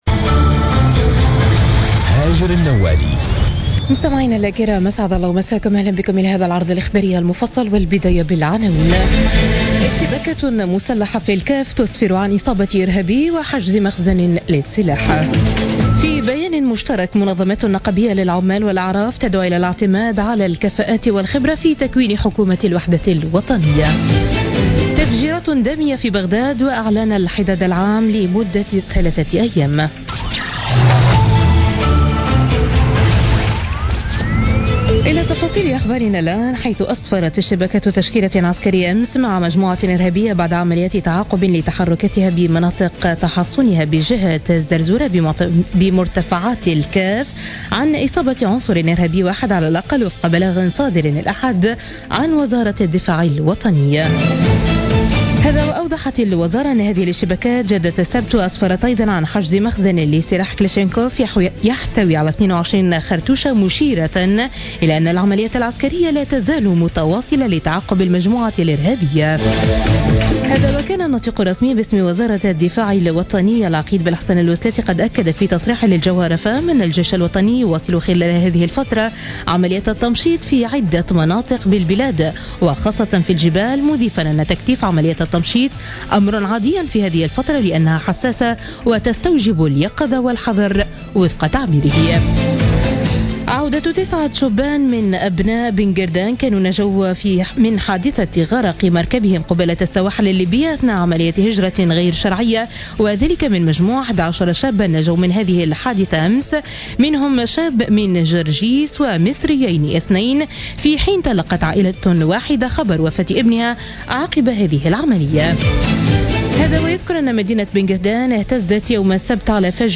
Journal Info 00h00 du lundi 4 Juillet 2016